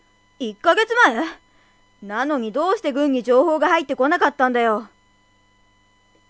ＳＡＭＰＬＥ　ＶＯＩＣＥ
イメージではちょっと低めの声。勢いがあるといいかな。